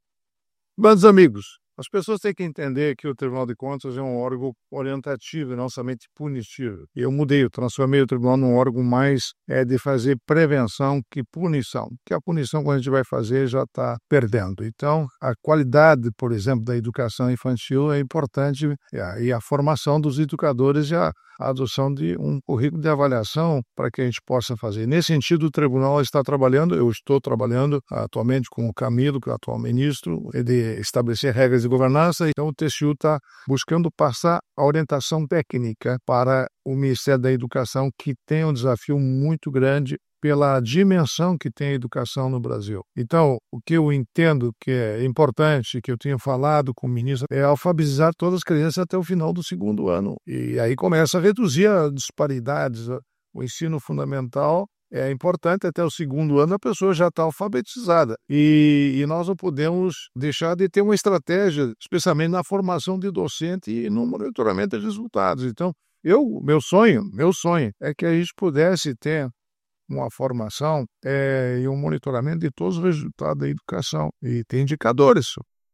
Comentário do ministro Augusto Nardes.